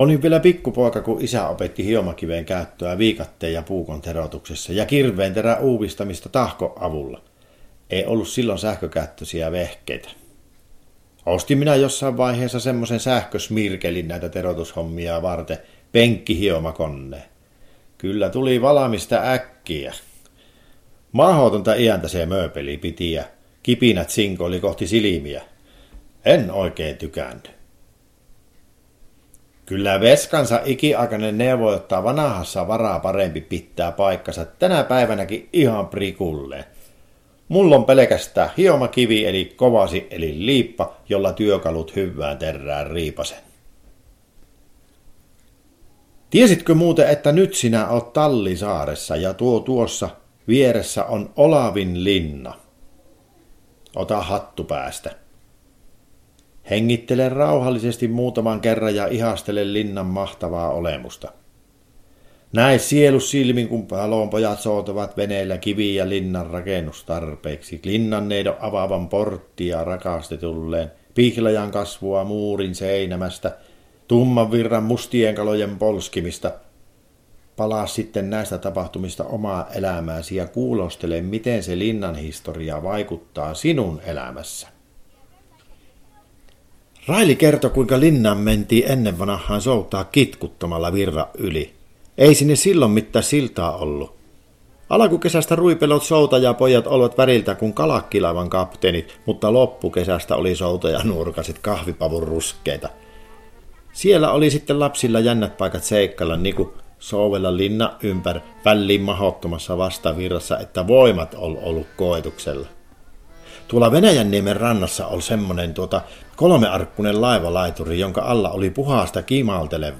Puhujana näyttelijä